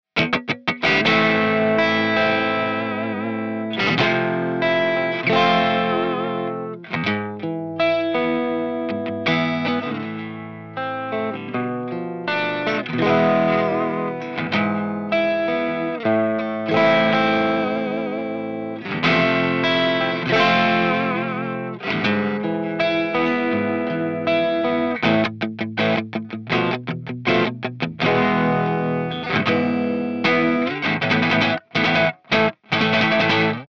Chords
RAW AUDIO CLIPS ONLY, NO POST-PROCESSING EFFECTS
Hi-Gain